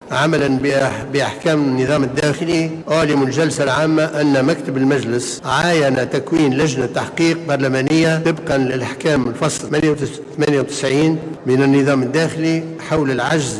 وجاء الإعلان في إطار الجلسة العامة المنعقدة اليوم، وذلك على إثر معاينة مكتب مجلس النواب لطلب تكوين اللجنة من طرف الكتل المعارضة.